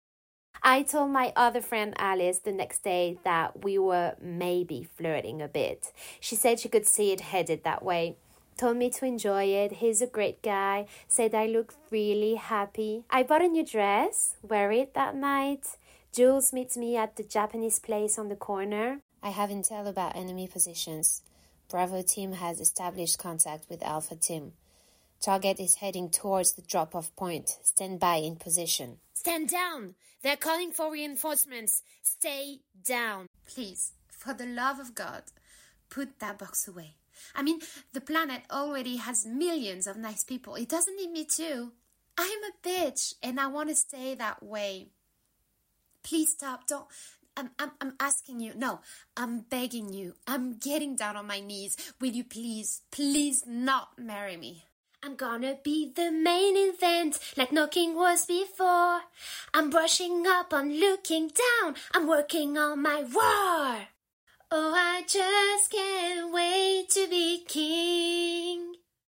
Voice demo english